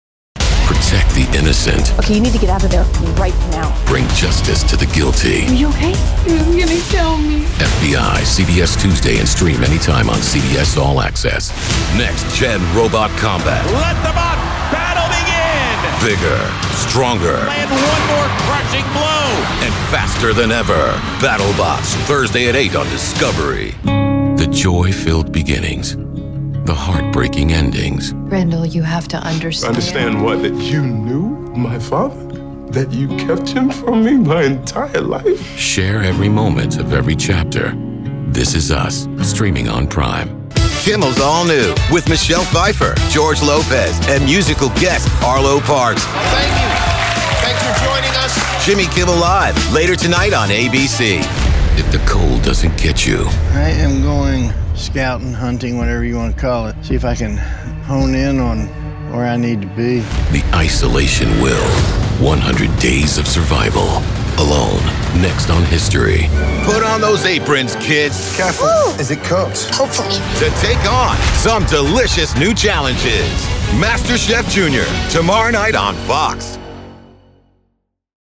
Authoritative, Razor Sharp, Witty and Conversational
Network Promo
Southern, NYC
Middle Aged